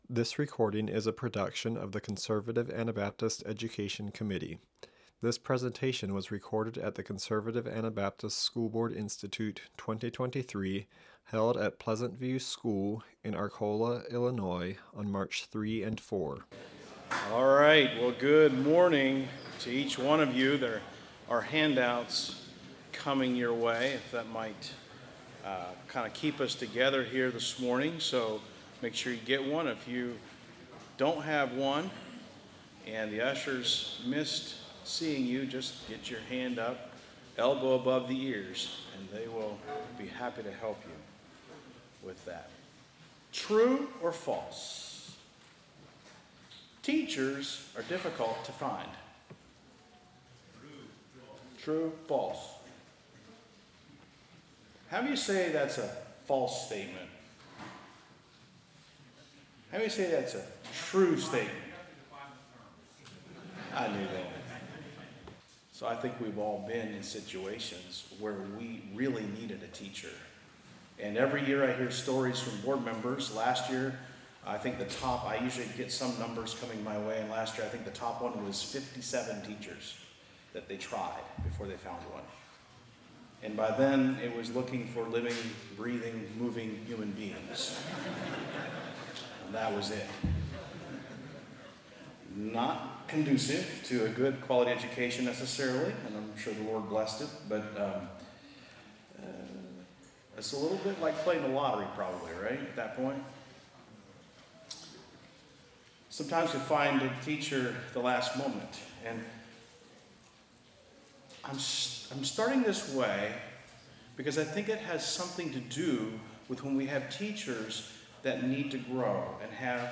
Home » Lectures » Addressing Teacher Deficiencies